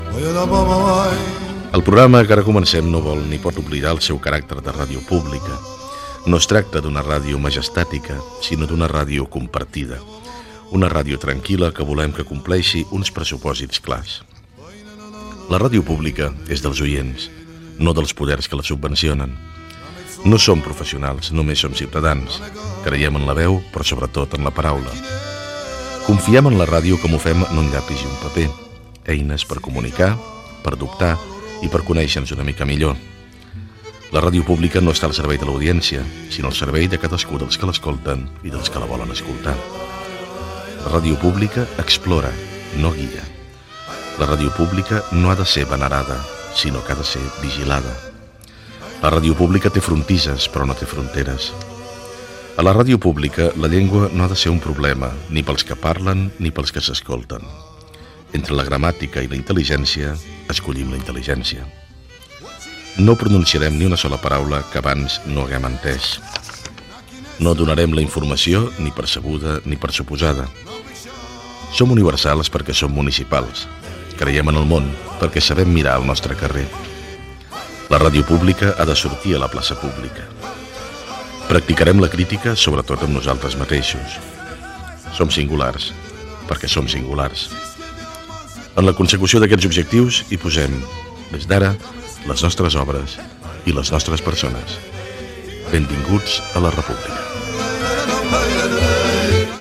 Info-entreteniment
Fragment extret del programa 1000 de la R-Pública, emès el 3 de febrer de 2005.